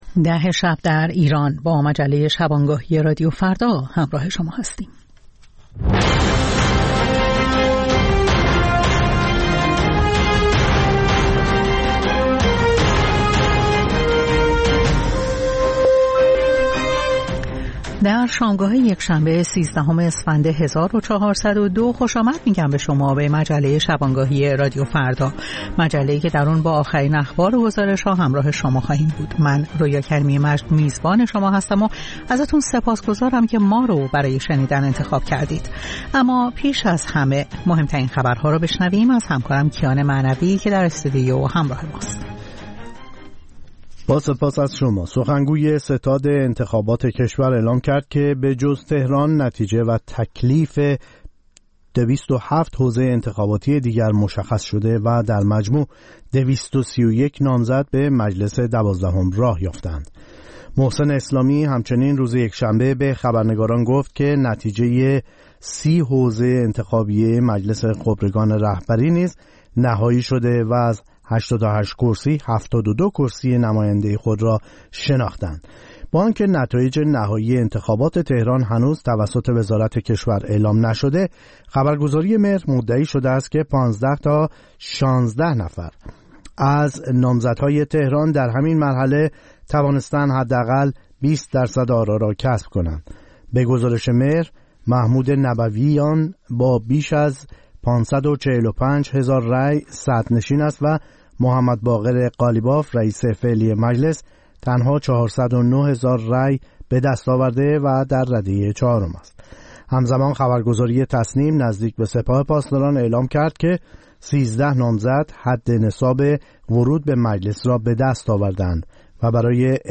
نیم ساعت با تازه‌ترین خبرها، گزارش‌های دست اول در باره آخرین تحولات جهان و ایران از گزارشگران رادیو فردا در چهارگوشه جهان، گفت‌وگوهای اختصاصی با چهره‌های خبرساز و کارشناسان.